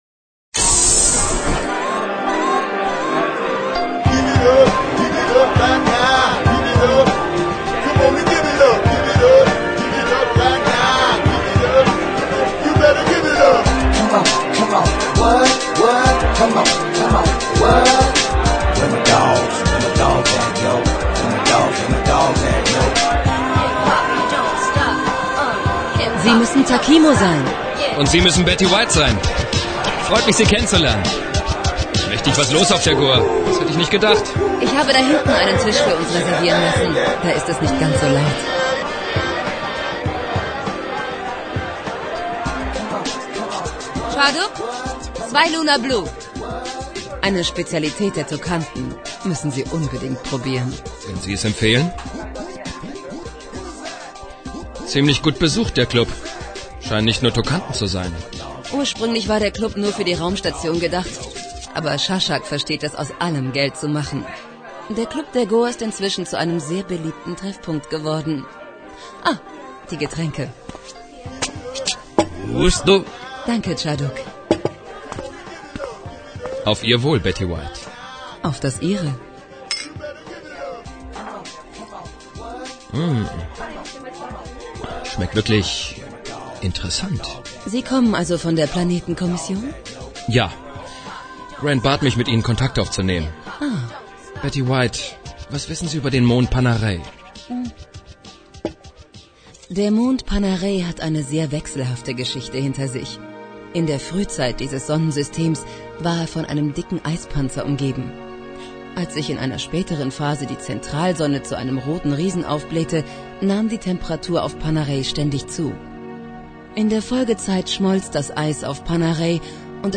Das aufwendige Sounddesign mit Geräuschen und Musik tut ein übriges, um den Zuhörer an den Stoff zu fesseln.
Hörprobe (mit freundlicher Genehmigung von Polaris Hörspiele, Berlin)